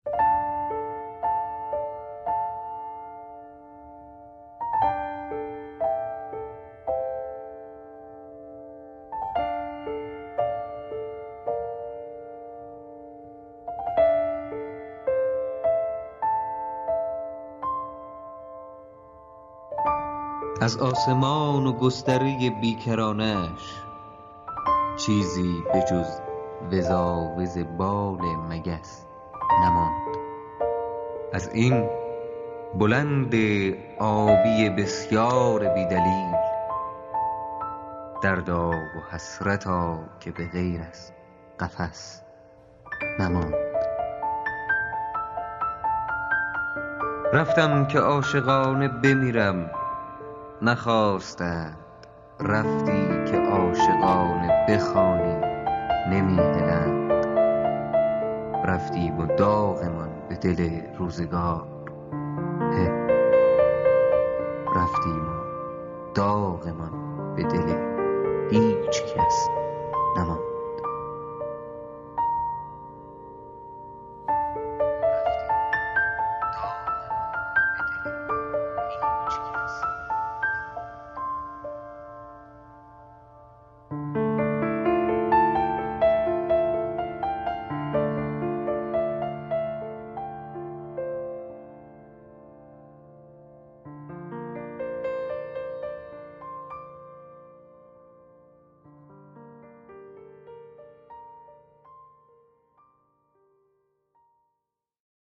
شعر برگزیده برای این چالش:
دانلود دکلمه برتر چالش